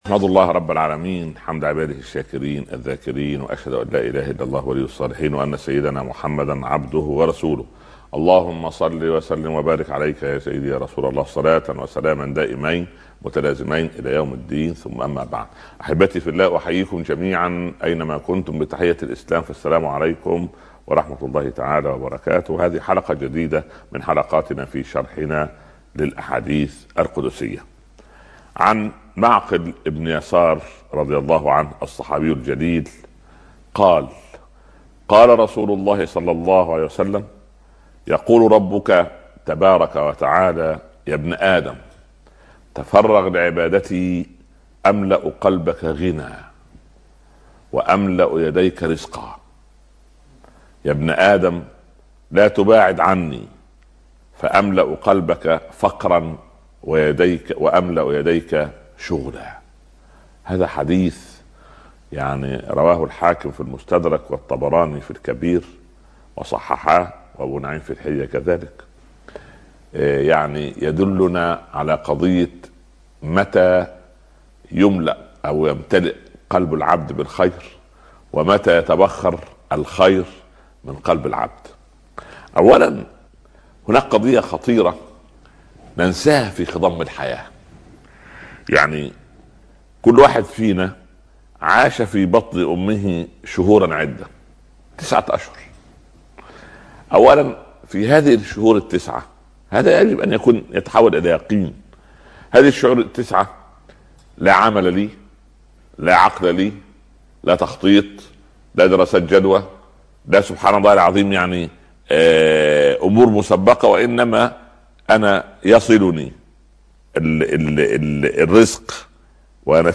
أرشيف الإسلام - أرشيف صوتي لدروس وخطب ومحاضرات د. عمر عبد الكافي